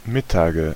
Ääntäminen
Ääntäminen Tuntematon aksentti: IPA: /ˈmɪtaːɡə/ Haettu sana löytyi näillä lähdekielillä: saksa Käännöksiä ei löytynyt valitulle kohdekielelle. Mittage on sanan Mittag monikko.